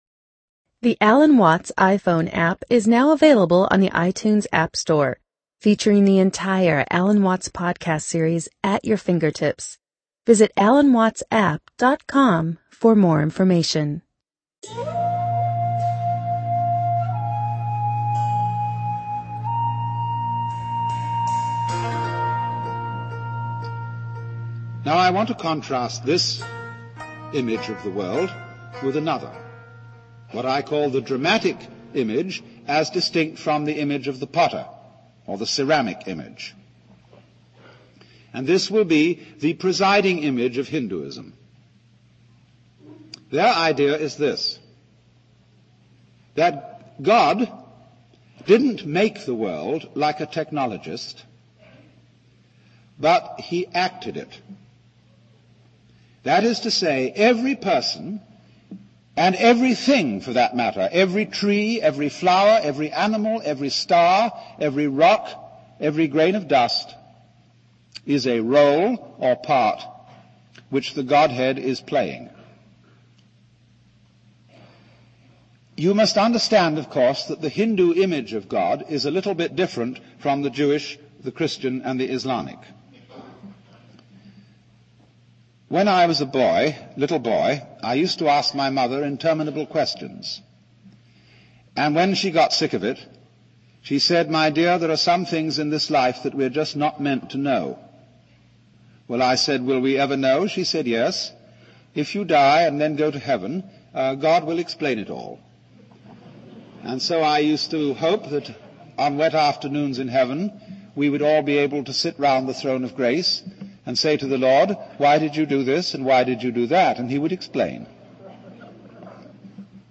You are listening to a sample of The Essential Lectures of Alan Watts.